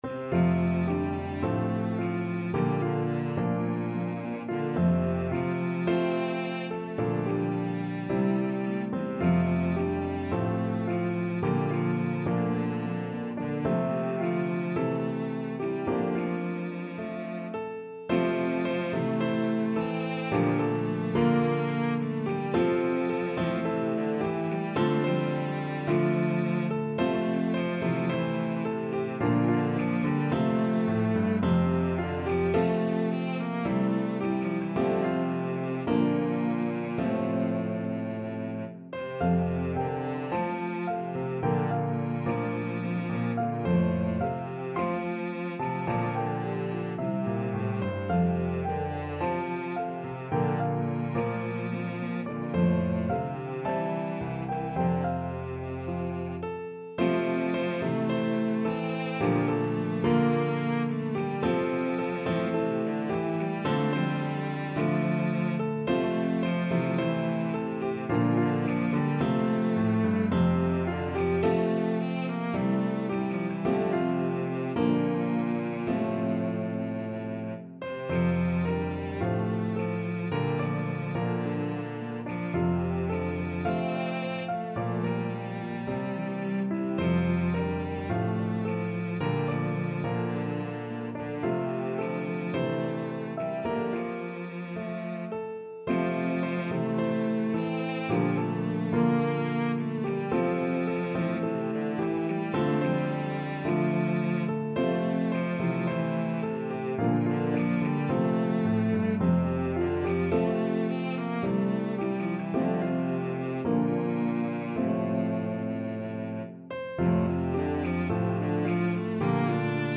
pedal harp
Harp, Piano, and Cello version